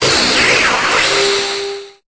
Cri de Necrozma dans Pokémon Épée et Bouclier.